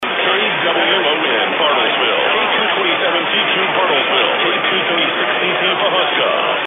DXing is the practice of receiving and identifying distant radio signals.
CLICK HERE FOR KWON's LEGAL ID HEARD IN LAPLAND